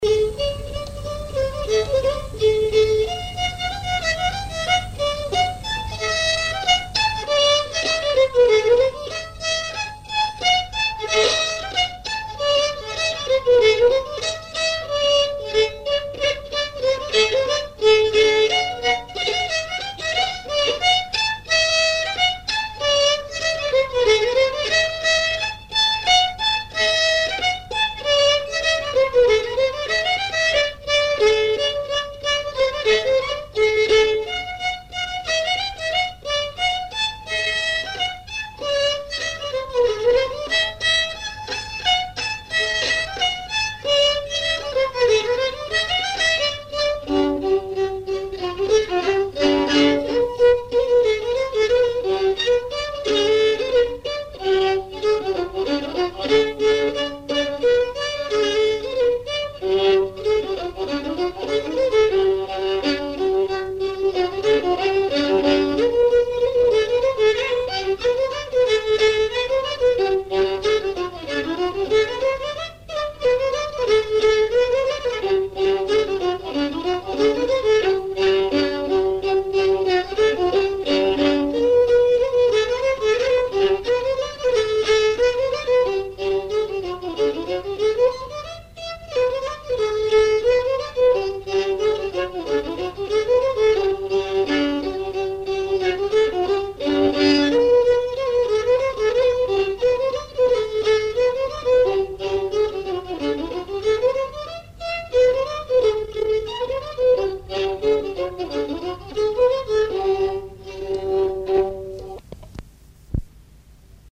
danse : scottich sept pas
enregistrements du Répertoire du violoneux
Pièce musicale inédite